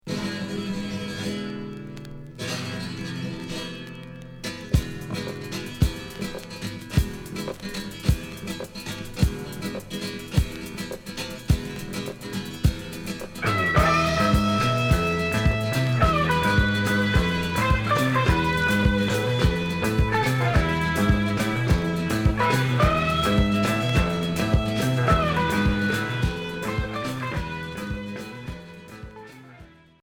Flamenco Rock